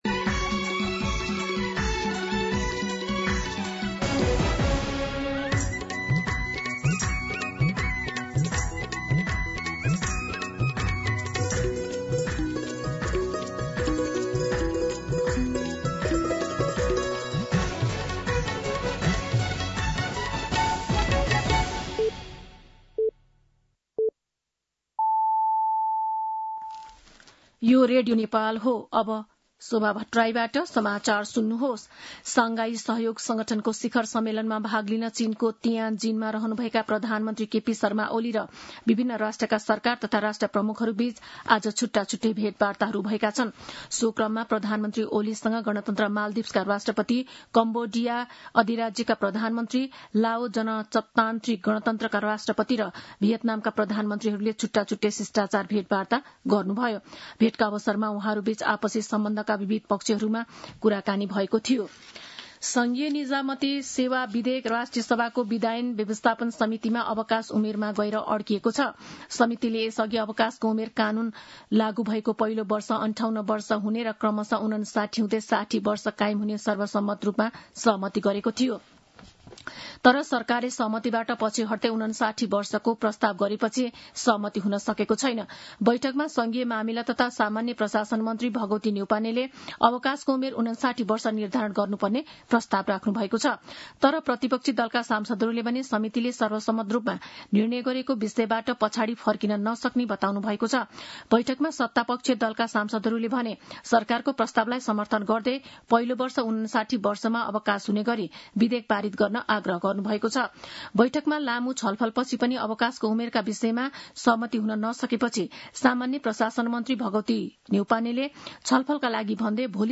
दिउँसो ४ बजेको नेपाली समाचार : १६ भदौ , २०८२
4pm-News-.mp3